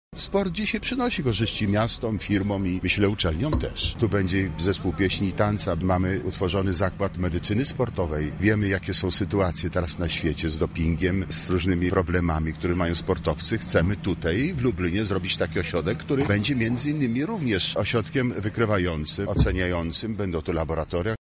Rozpiera mnie duma, bo jest to obiekt klasy europejskiej – mówi profesor Andrzej Drop, rektor Uniwersytetu Medycznego w Lublinie.